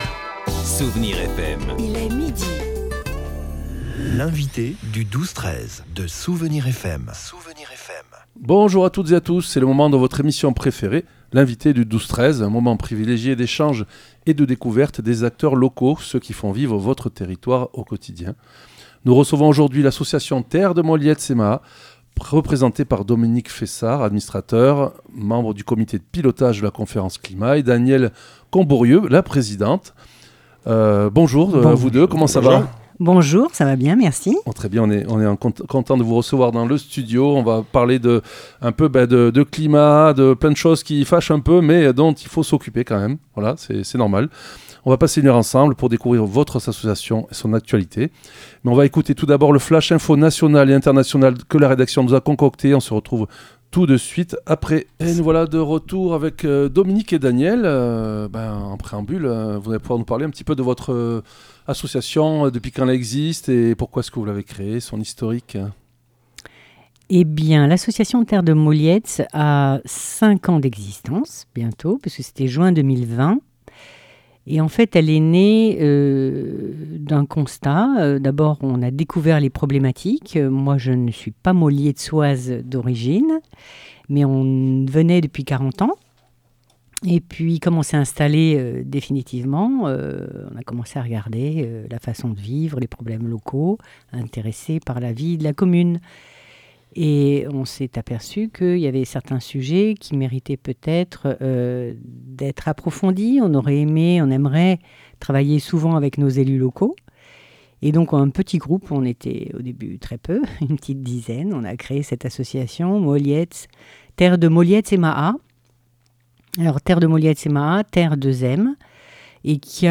L'invité(e) du 12-13 de Soustons recevait aujourd'hui Terre2M (Terre de Moliets et Maâ).